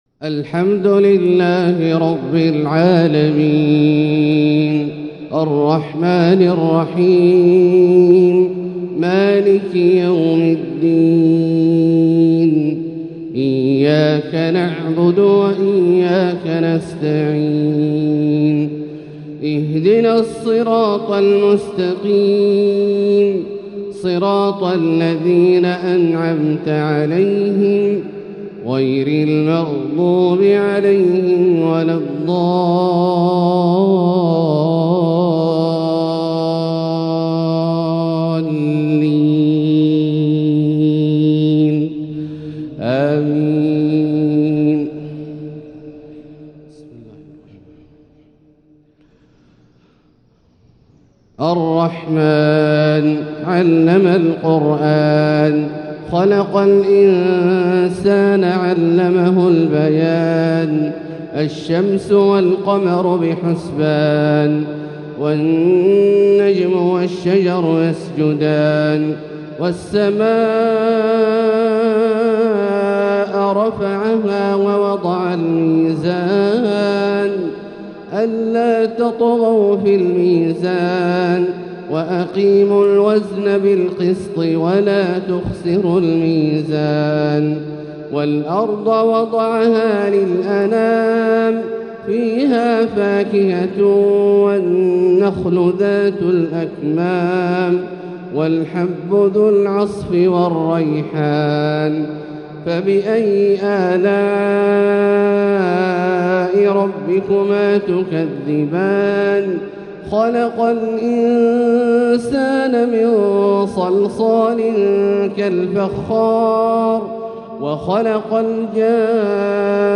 Fajr prayer from Surat Alrahman 2-3-2024 > 1445 H > Prayers - Abdullah Al-Juhani Recitations